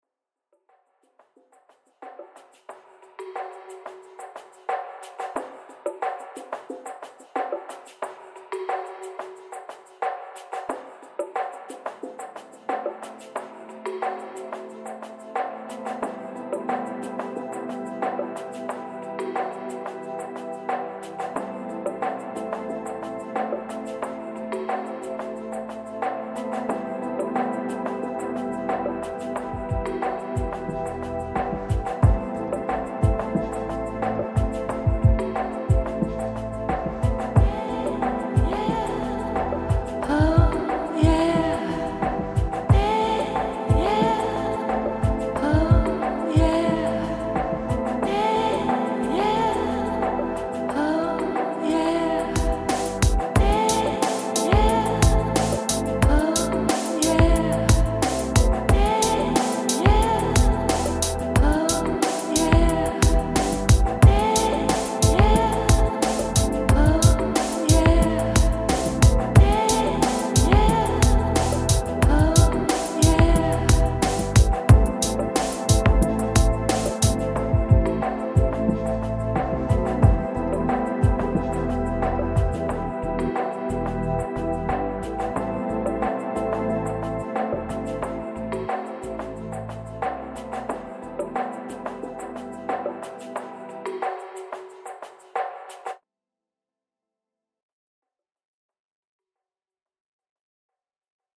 7-chillisland.mp3